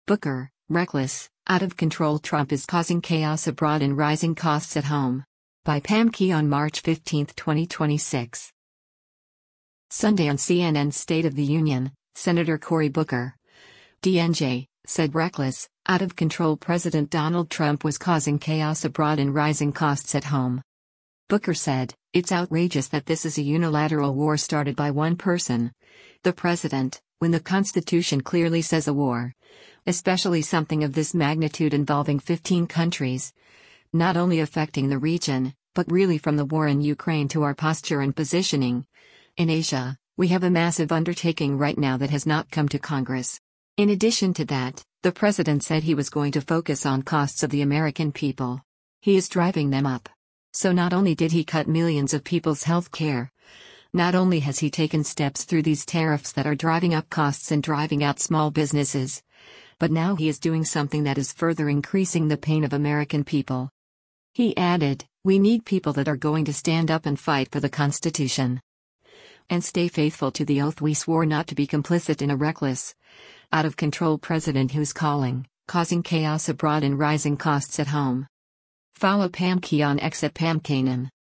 Sunday on CNN’s “State of the Union,” Sen. Cory Booker (D-NJ) said “reckless, out-of-control” President Donald Trump was “causing chaos abroad and rising costs at home.”